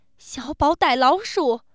fear